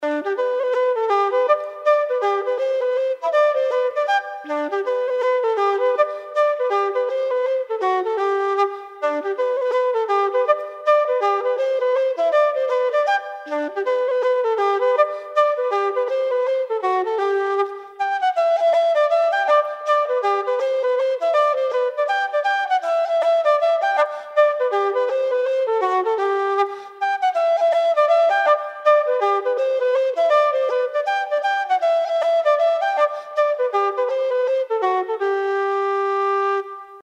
Traditional Irish Music - learning resources
Traditional Irish Music -- Learning Resources Sonny Brogan's #2 (Mazurka) /